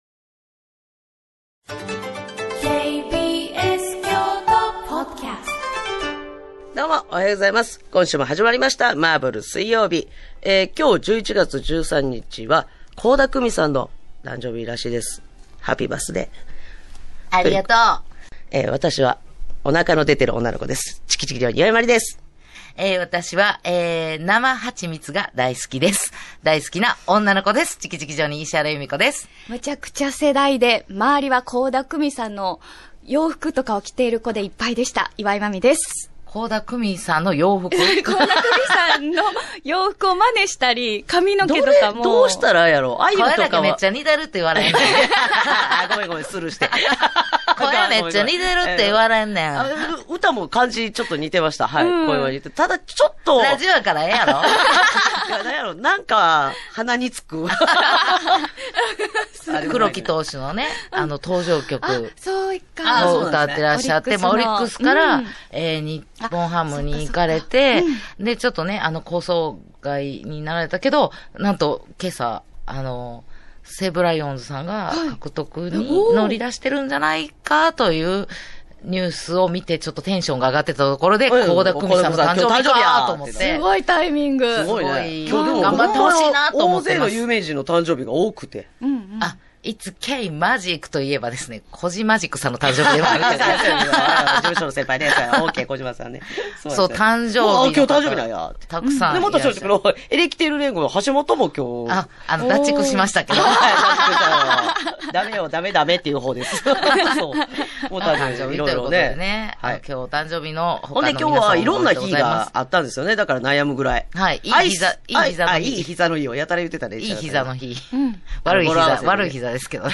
【KBS京都ラジオ 毎週水曜日 10:00〜14:00 オンエア】アラサー・アラフォーの独身女子たちが送るバラエティラジオ。グルメや旅行の話題から、今すぐ言いたいちょっとした雑学、みんな大好き噂話まで気になる話題が満載。